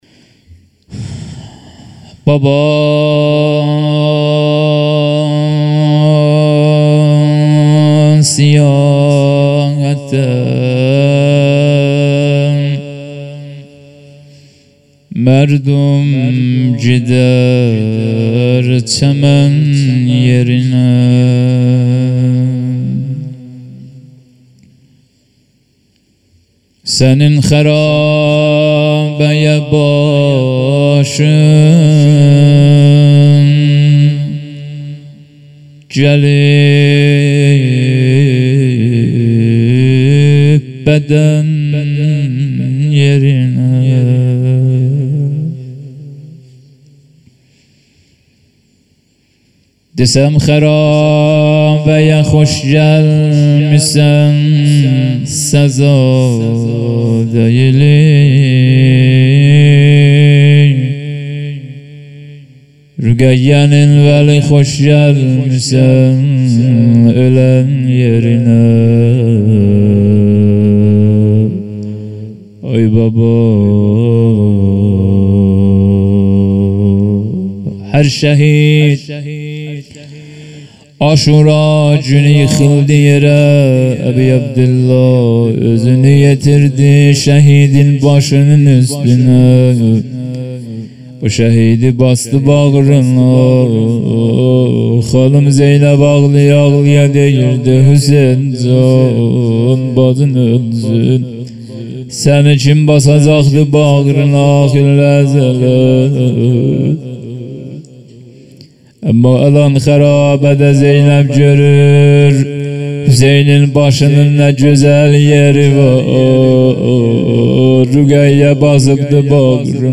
روضه اول